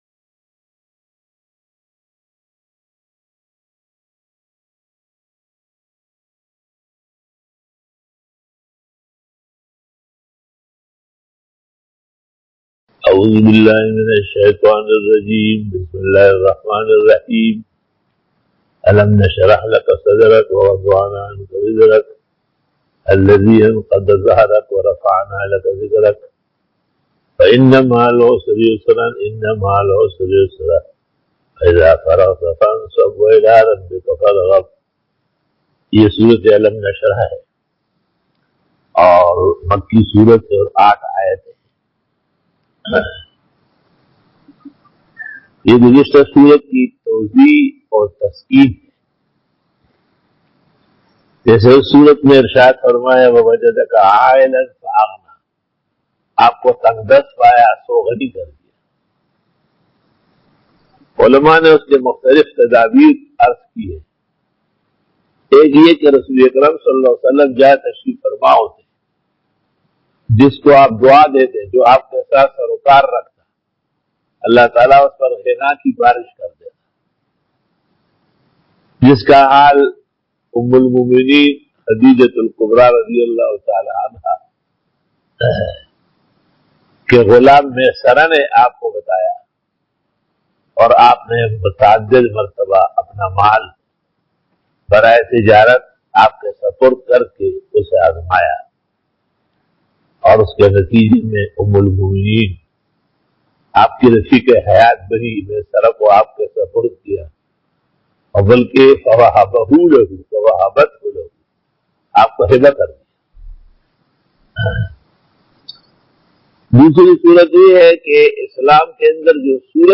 Bayan by